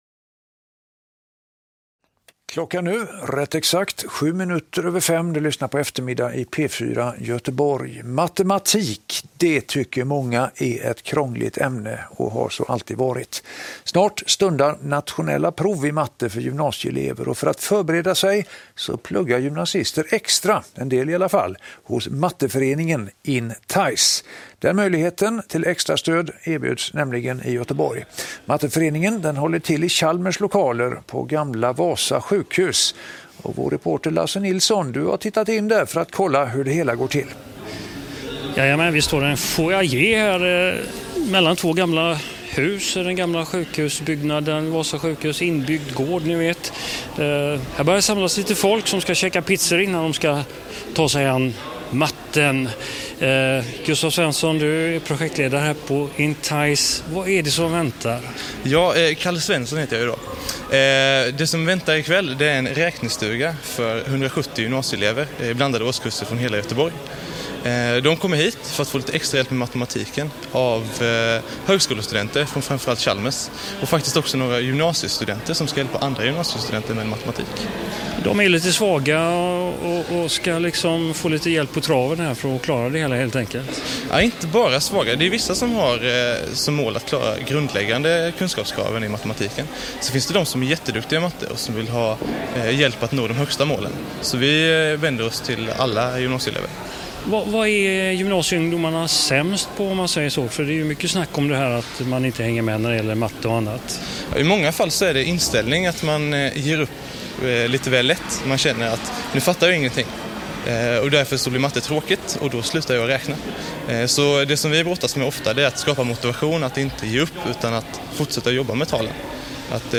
Sveriges Radio P4 Göteborg – Intervju från Intize pluggkväll (2015)
Intervju-från-Intize-pluggkväll-Sveriges-Radio-P4-Göteborg.mp3